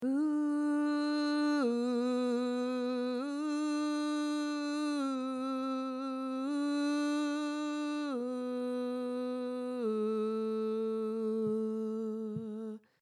JazzGloriaOohTenor.mp3